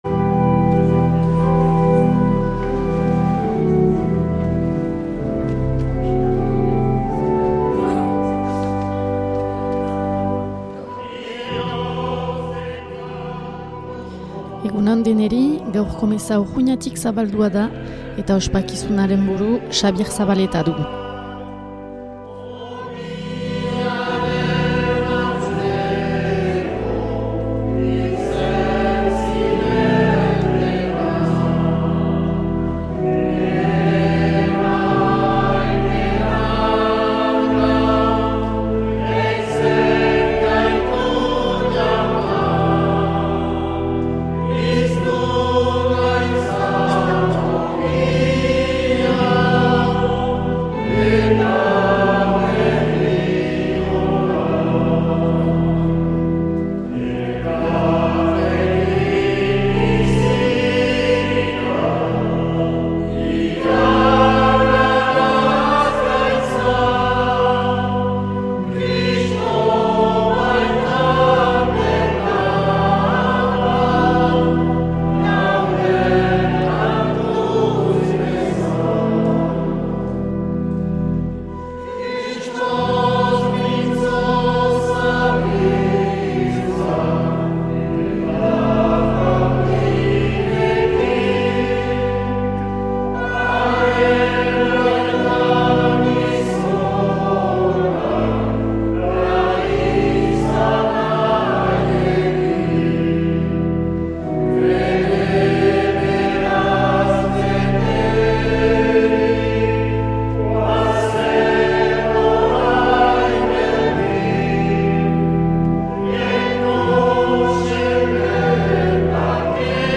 2025-10-26 Urteko 30. Igandea C - Urruña